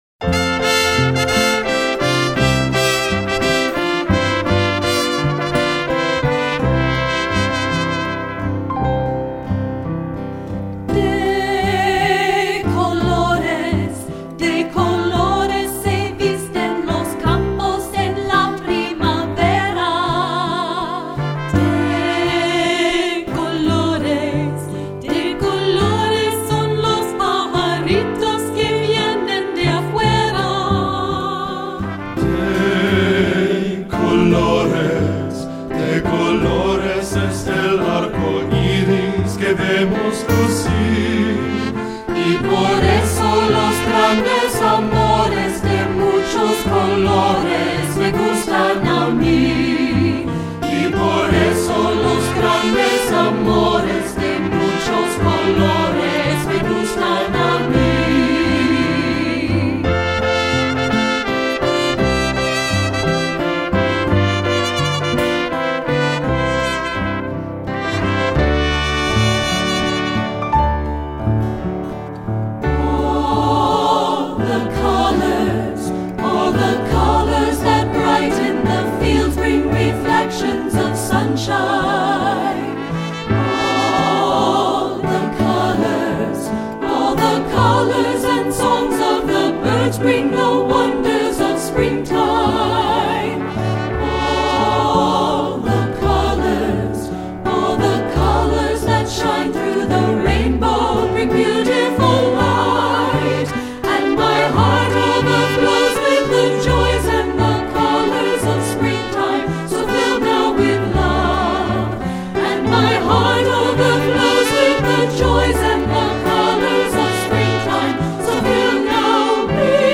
Composer: Mexican Folk Song
Voicing: 3-Part Mixed